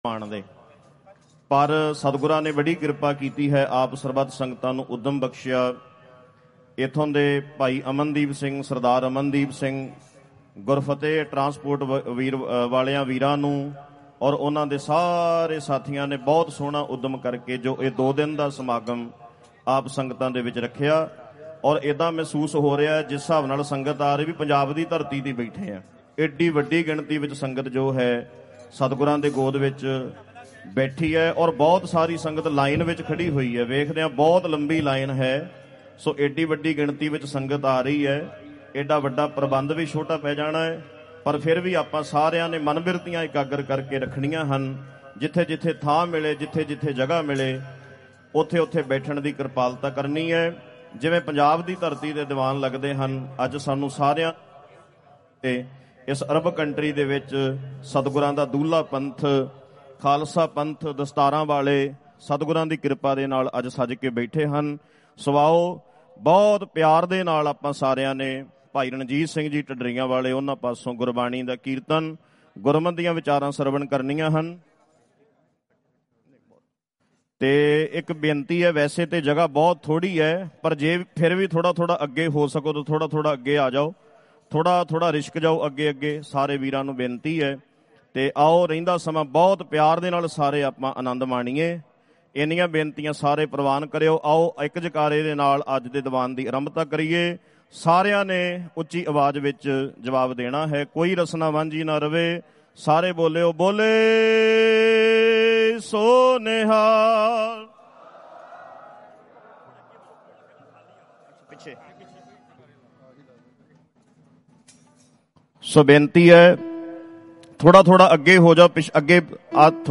Live aweer ras al khor dubai gurmat samagam 26 sep 2025